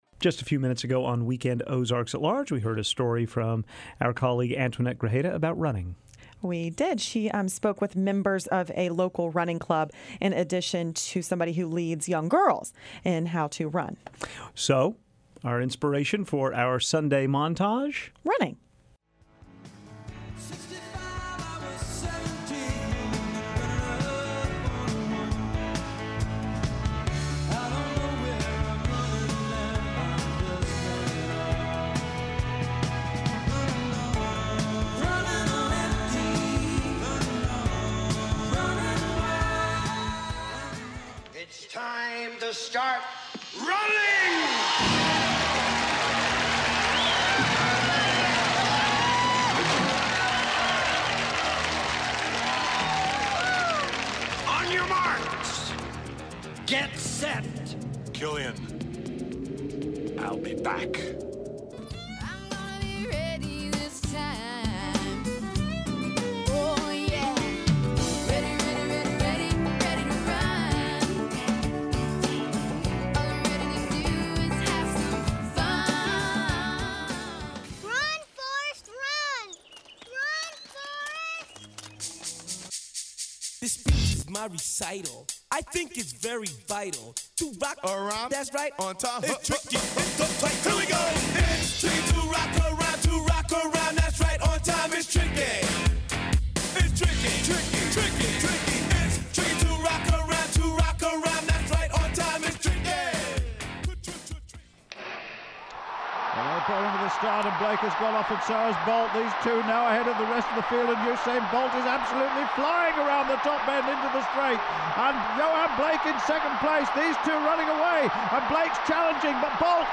Here is our collection of clips dedicated to running. 1. Jackson Browne sings about Running on Empty. 2.
Jerry Seinfeld explains how he won the big race. 10. Bruce Springsteen is a scared and lonely rider in Born to Run.